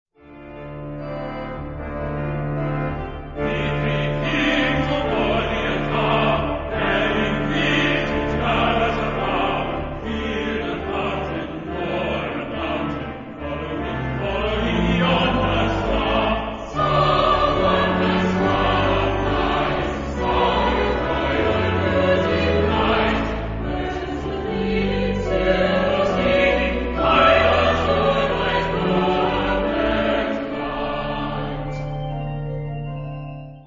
Genre-Style-Forme : Sacré ; Motet
Caractère de la pièce : andante
Type de choeur : SAH  (3 voix mixtes )
Instrumentation : Piano  (1 partie(s) instrumentale(s))
Instruments : Piano (1)